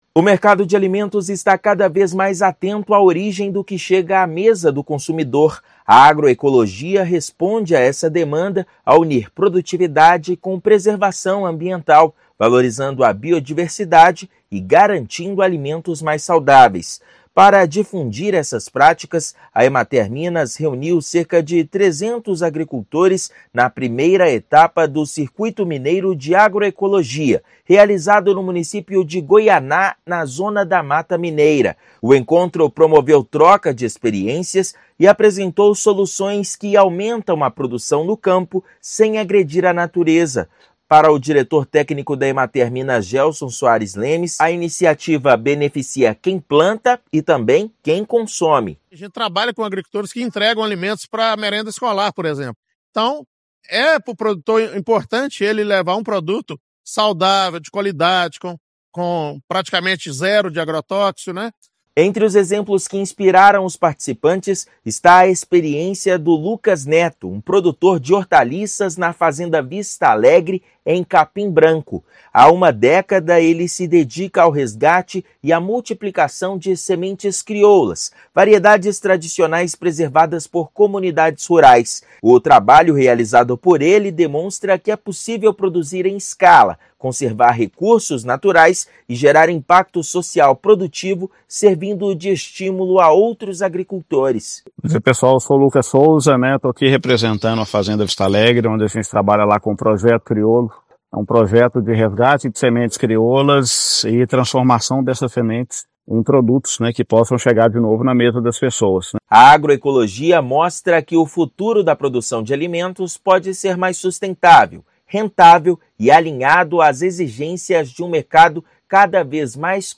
O assunto foi destaque na I etapa estadual do Circuito Mineiro de Agroecologia promovida pela Emater-MG. Ouça matéria de rádio.